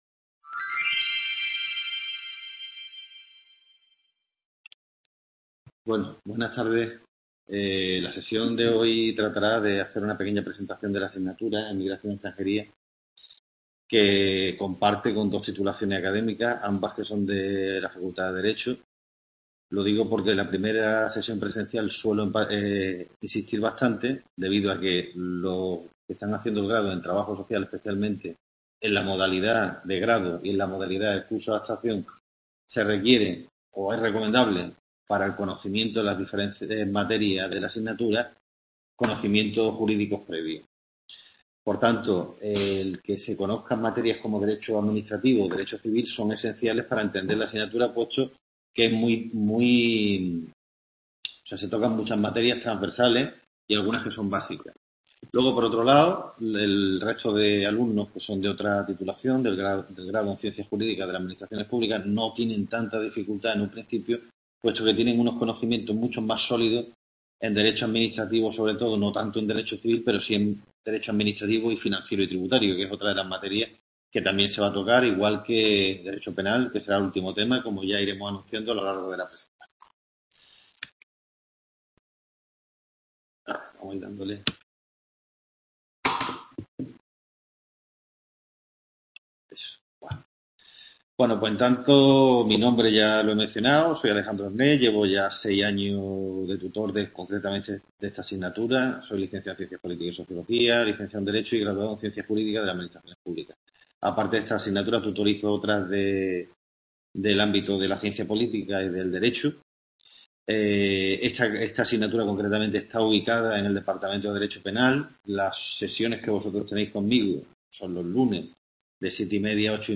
Sesión de Presentación
Video Clase